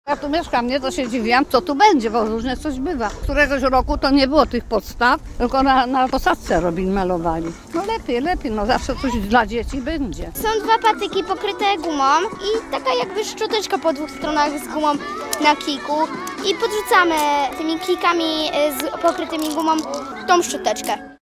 Nazwa Plik Autor Mówią okoliczni mieszkańcy audio (m4a) audio (oga) Przy okazji zaplanowanych atrakcji mieszkańcy mogą wyrazić swoją opinię na temat zmian, jakie należałoby wprowadzić na Starym Rynku.